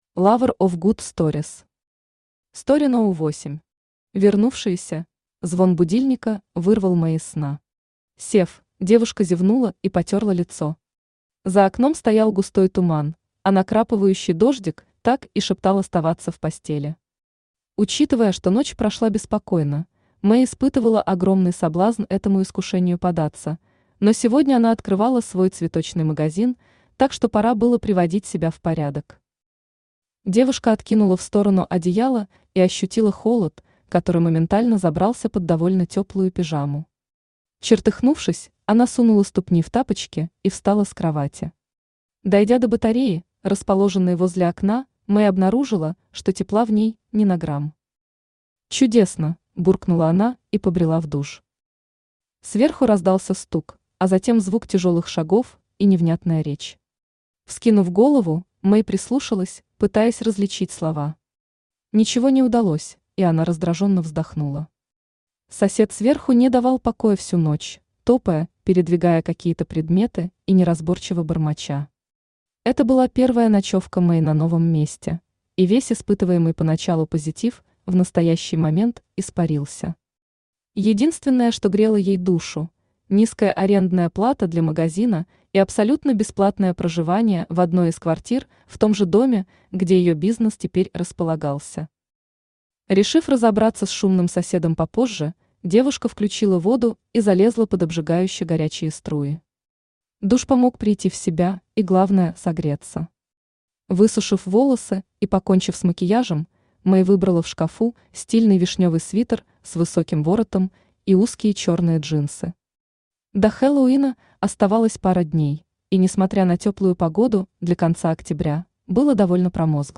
Читает: Авточтец ЛитРес
Аудиокнига «Story № 8. Вернувшиеся». Автор - Lover of good stories.